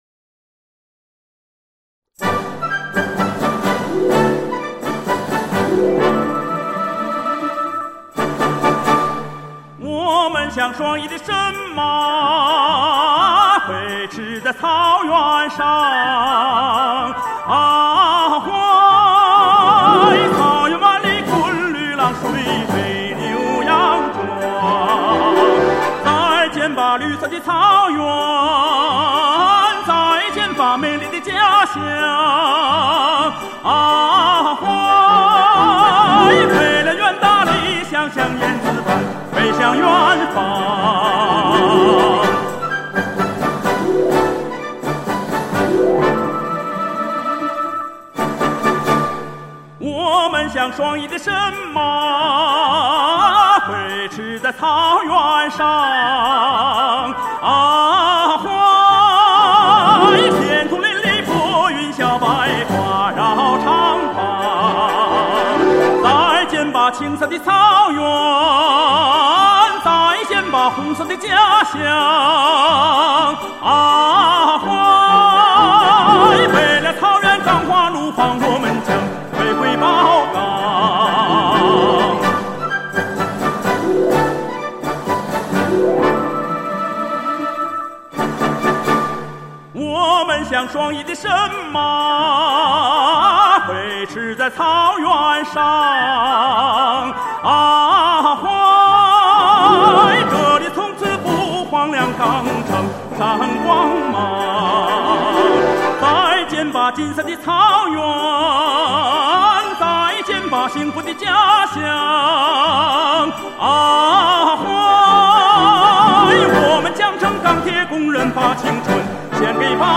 男高音歌唱家